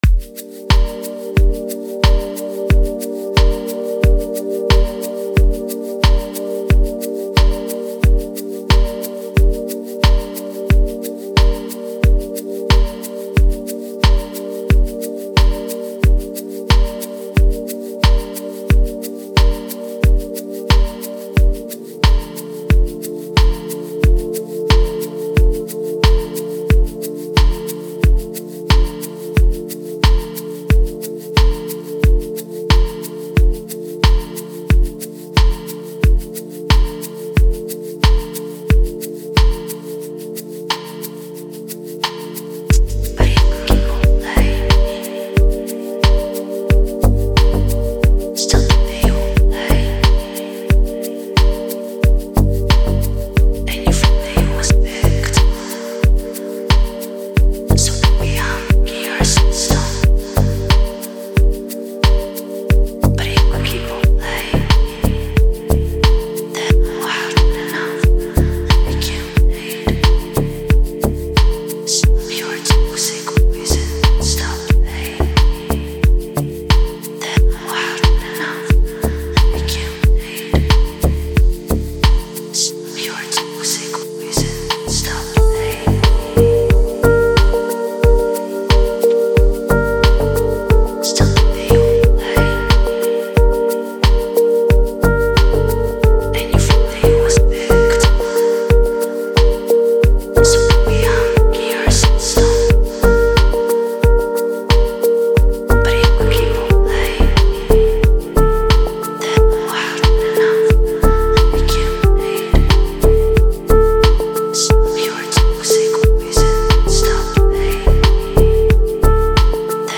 который сочетает в себе элементы R&B и поп-музыки.
выделяется своим уникальным голосом и искренним исполнением